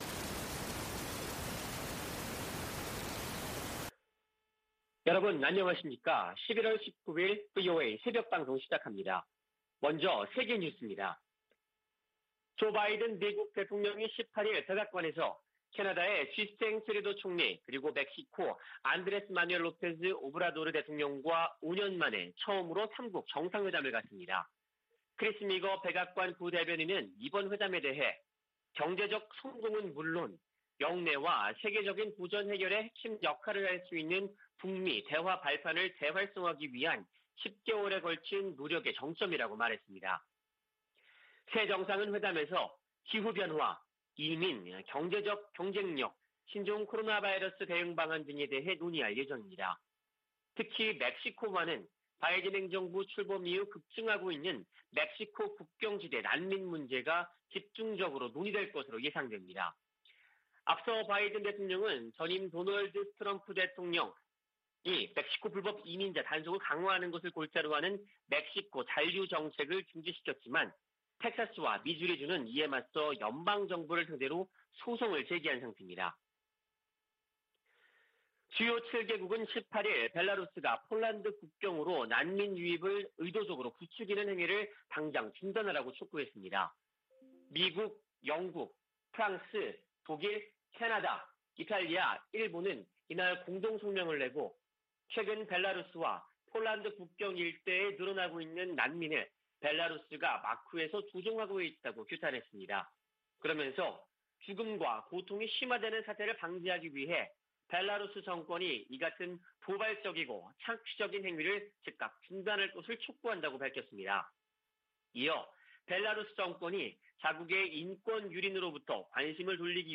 VOA 한국어 '출발 뉴스 쇼', 2021년 11월 19일 방송입니다. 유엔총회 제3위원회가 북한 내 인권 침해에 대한 책임 추궁 등을 강조한 북한인권 결의안을 표결 없이 합의 채택했습니다. 미국 국무부가 북한을 종교자유 특별우려국으로 재지정했습니다.